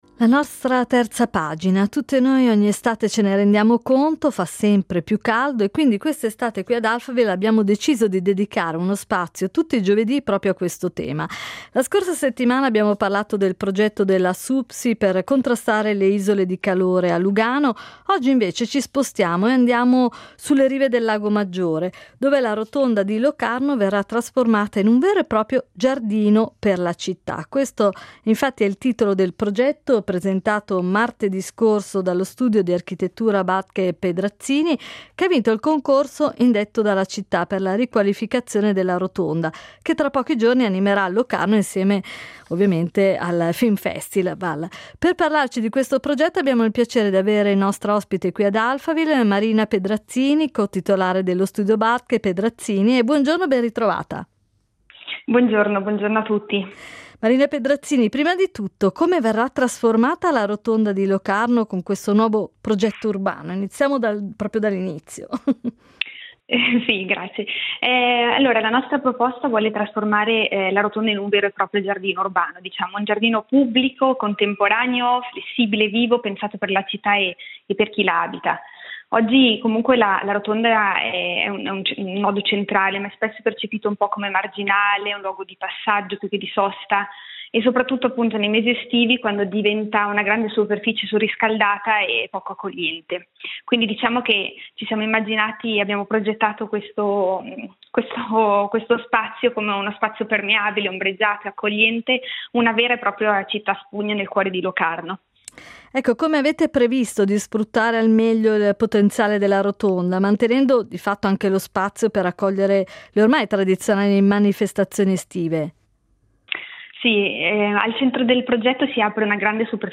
nostra ospite ad Alphaville.